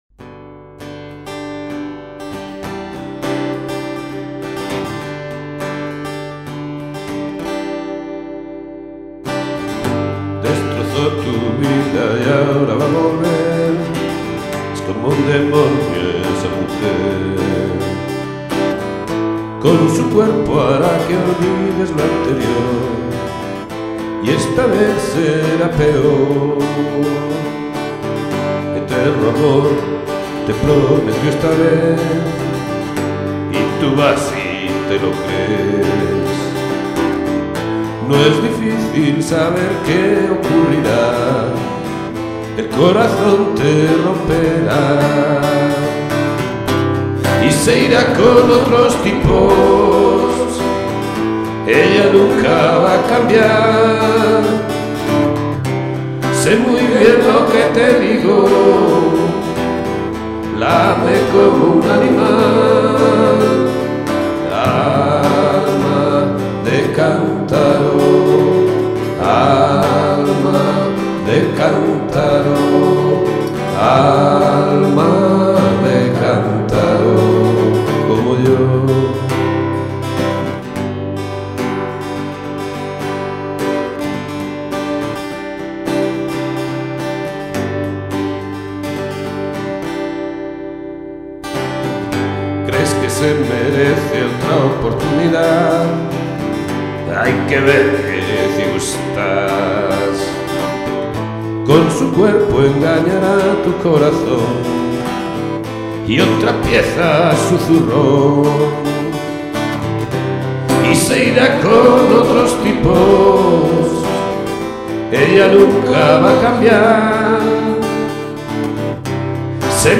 Acoustic guitar and voice.